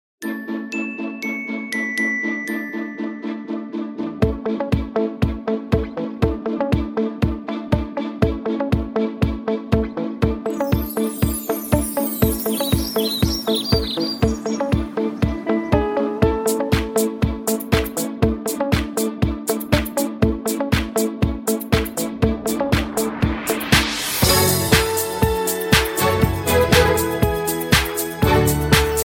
Two Semitones Up